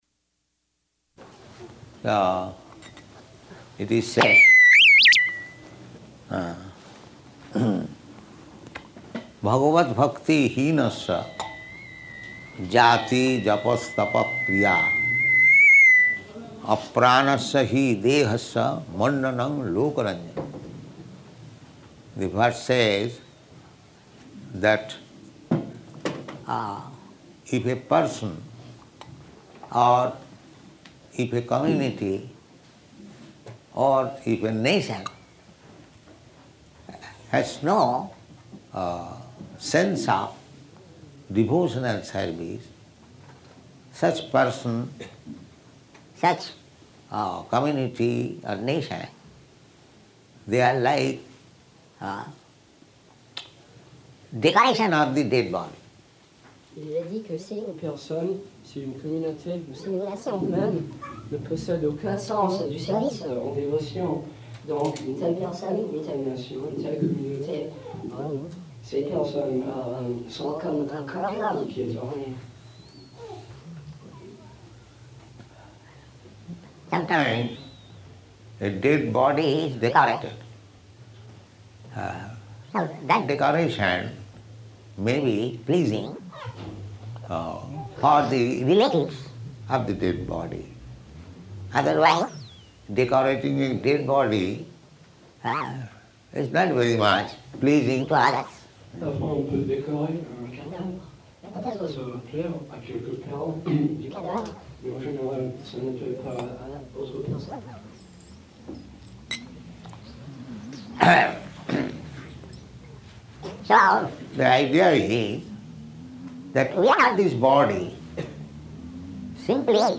Type: Lectures and Addresses
Location: Paris
[poor recording] [translated simultaneously into French by devotee]